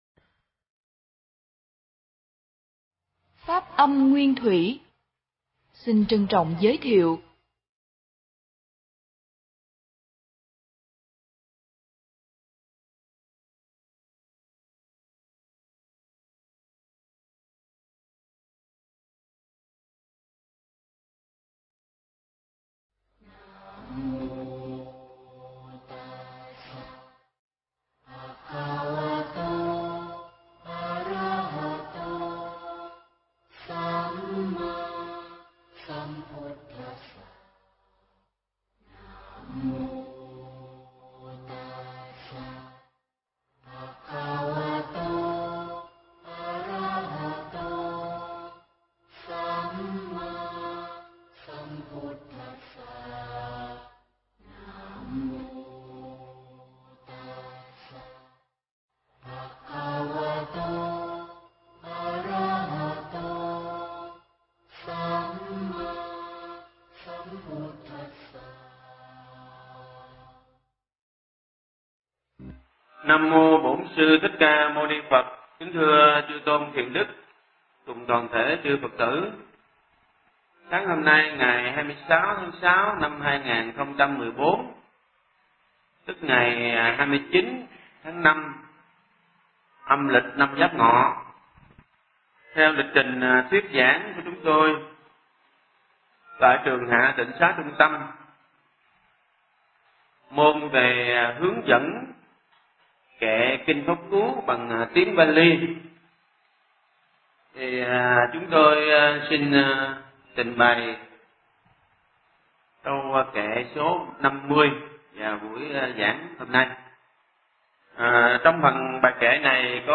Nghe Mp3 thuyết pháp Kinh Pháp Cú 50